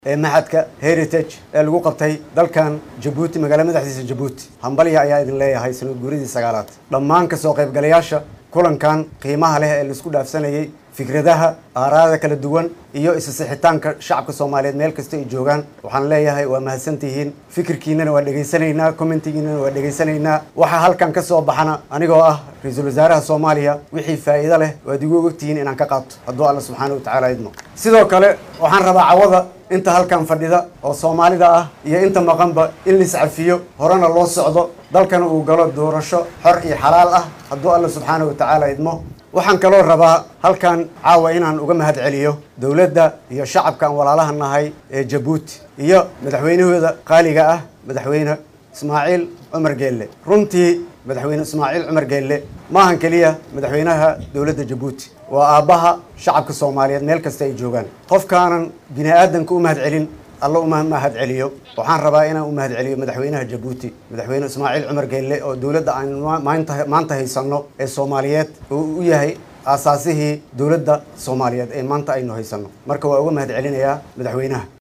DHAGEYSO:Ra’iisul wasaaraha Soomaaliya oo khudbad ka jeediyay madasha aragti wadaaga Soomaaliyeed
Ra’iisul Wasaaraha Xukuumadda Federaalka Soomaaliya Maxamad Xuseen Rooble oo ku sugan dalka Jabuuti ayaa ka sheegay munaasabad halkaasi ka dhacday inuu tixgelin doona wixii dan u ah Soomaaliya ee kasoo baxa Shirka Machadka Heritage.